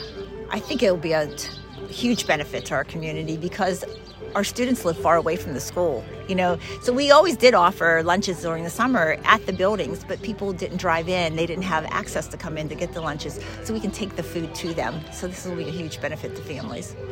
The Chevy Chase Community Center held a ceremony this morning highlighting the donation of a food delivery van.